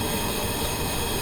Gas Burn Loop 03.wav